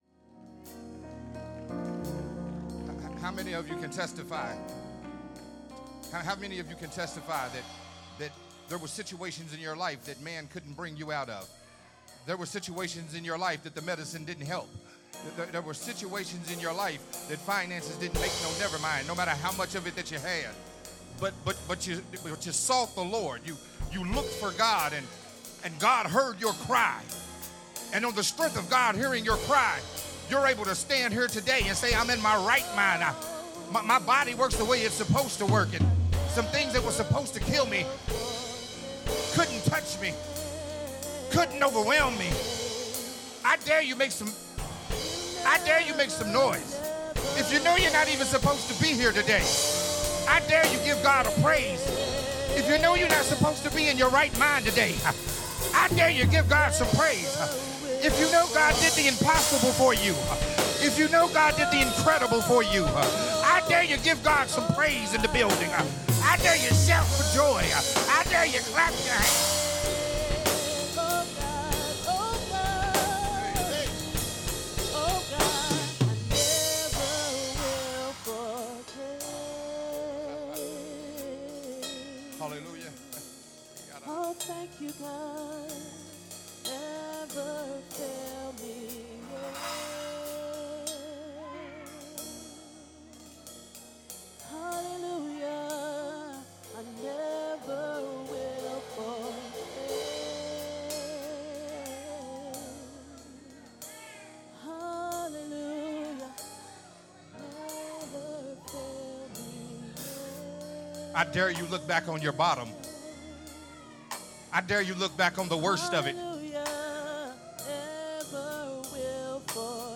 Sunday Morning Worship Service message